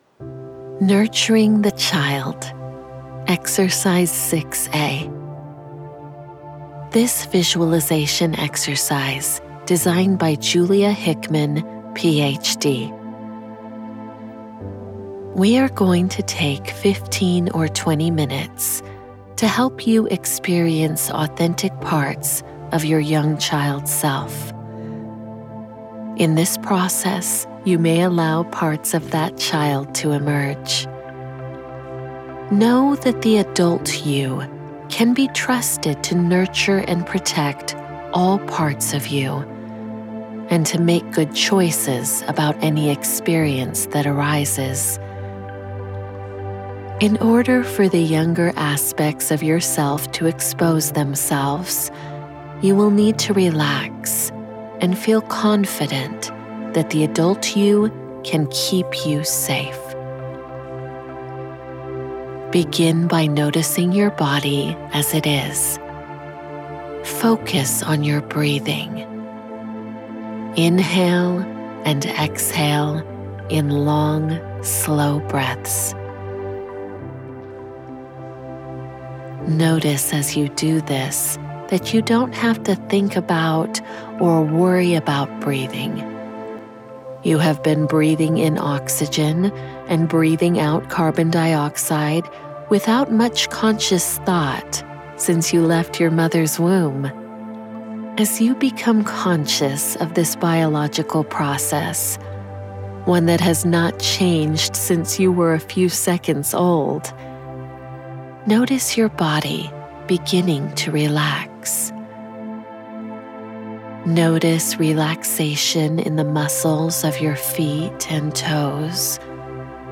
Nurturing the Child: Guided Visualization – Truehome Workshop